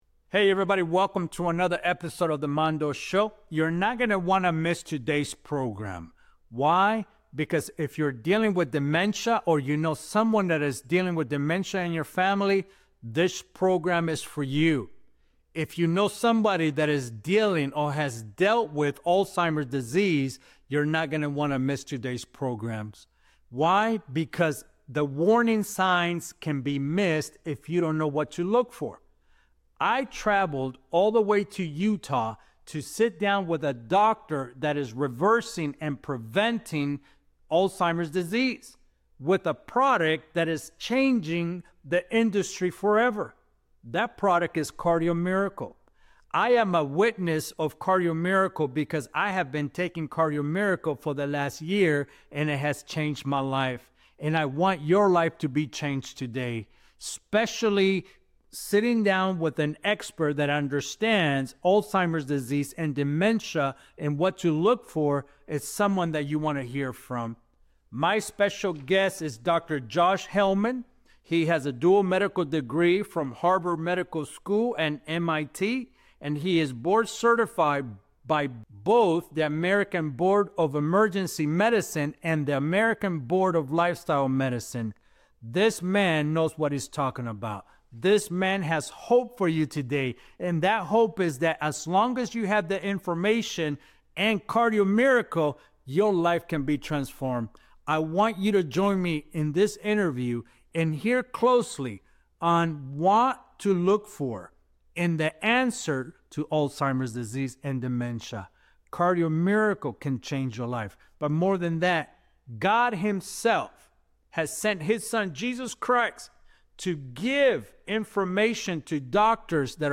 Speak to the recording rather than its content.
filmed on location at the stunning Homestead Studios in Utah